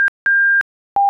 Сигналы могут комбинироваться, таким образом, при перегреве во время работы от батареи, в конце передачи звучит тройной сигнал, короткий высокий, длинный высокий, длинный низкий.